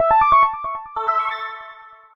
Techmino/media/effect/chiptune/welcome.ogg at beff0c9d991e89c7ce3d02b5f99a879a052d4d3e